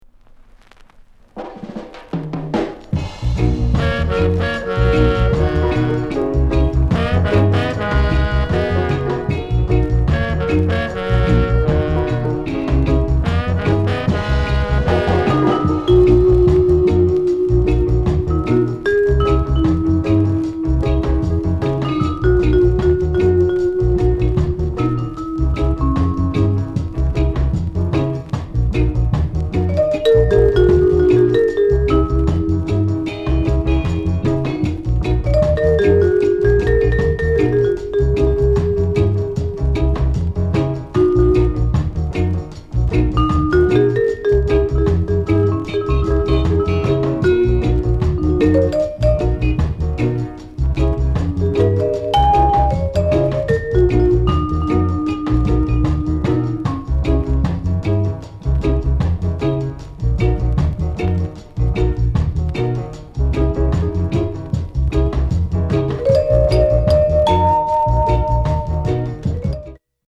NICE INST